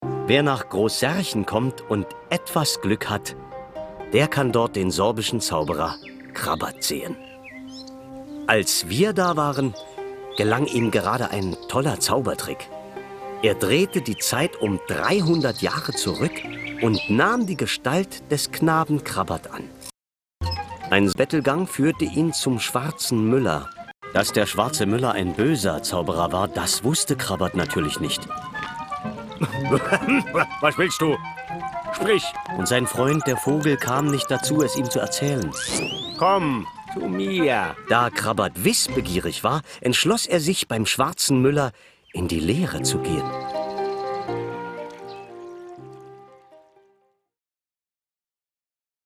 Geschichten, Kabarettszenen, Parodien und Minihörspiele.
Geschichte vom Krabat, für Mediahaus Kreischa.